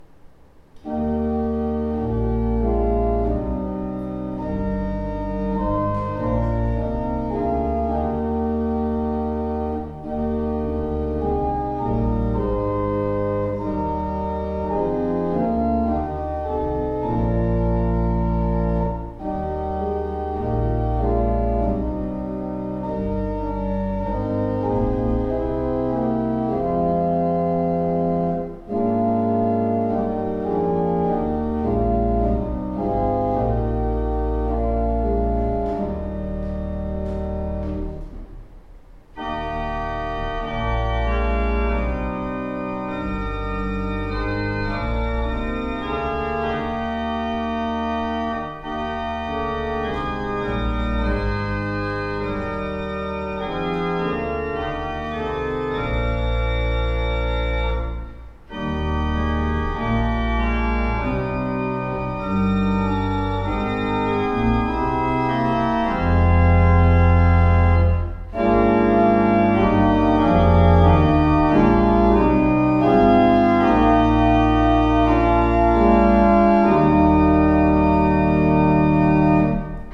Organist Gloucestershire, UK